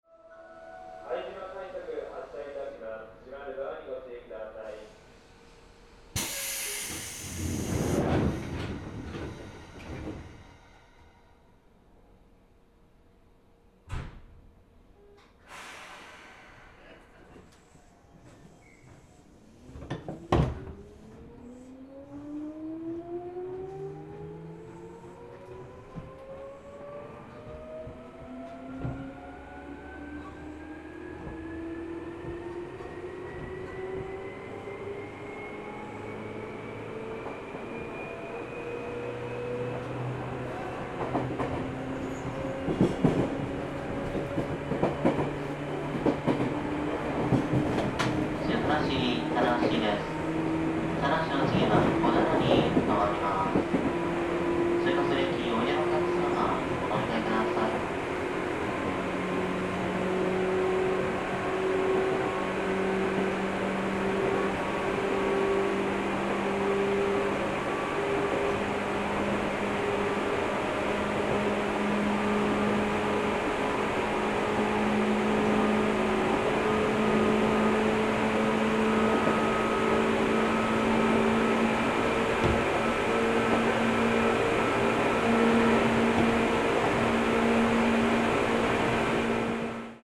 鉄道走行音ＣＤ 真栄館［既刊情報・西武鉄道2000N系界磁チョッパ車 新宿・拝島線 拝島快速］
録音車両：クモハ２５２５
今回のCDは、その2000N系を平成24（2012）年のダイヤ改正で廃止となった新宿線系統独自種別・拝島快速で録音しました。界磁チョッパ特有の加減速時の音をお楽しみください。